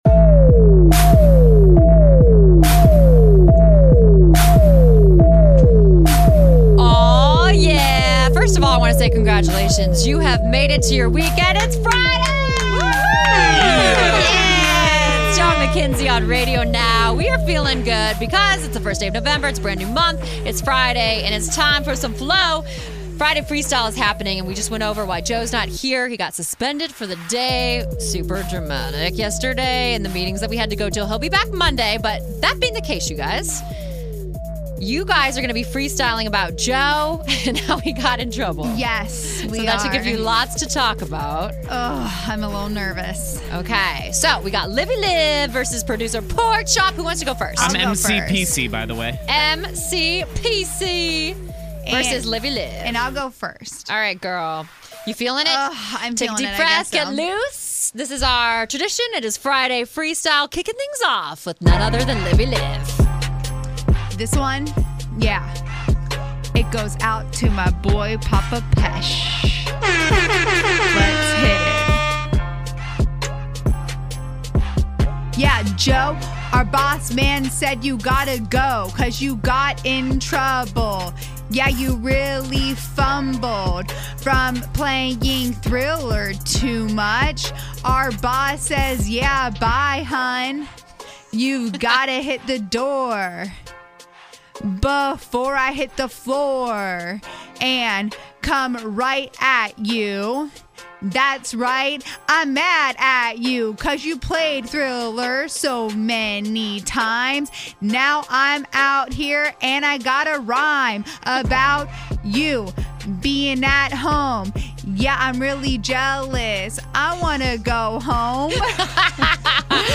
Freestyle Friday Rap Battle